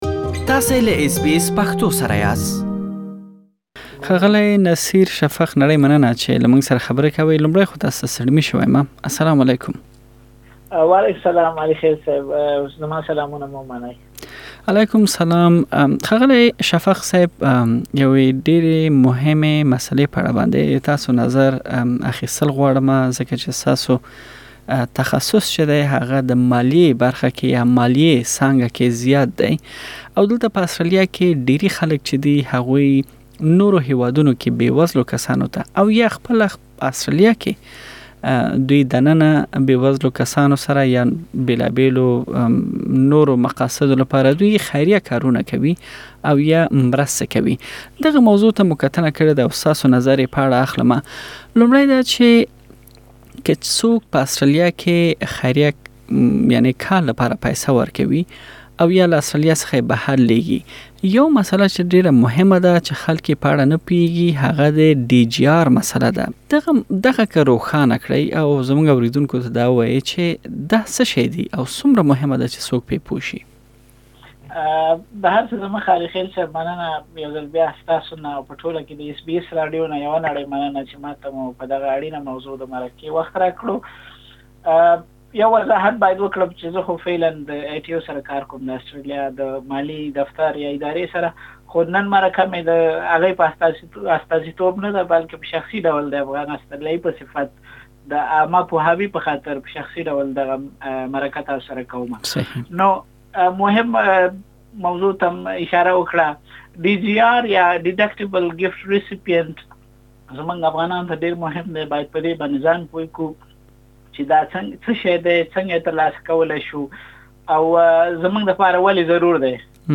پدې مرکه کې: څنګه خيريه مرستې پيسې بيرته ترلاسه کړئ؟